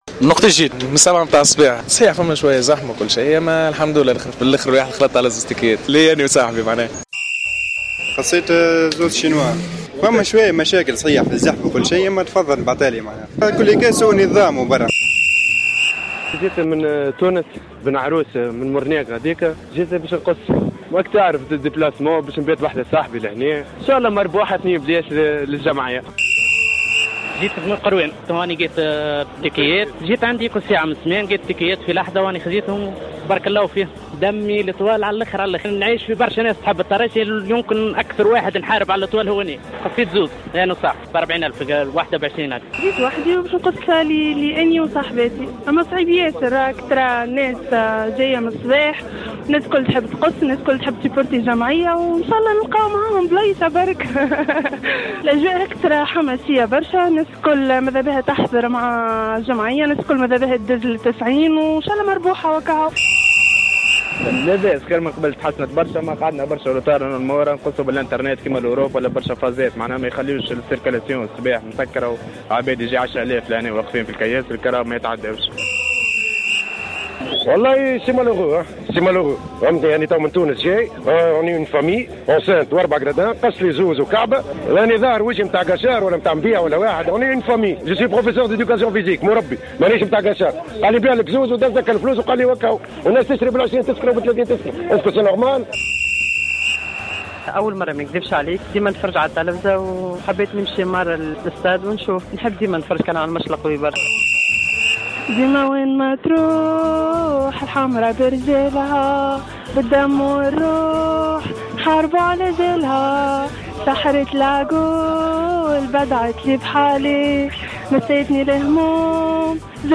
جماهير النجم الساحلي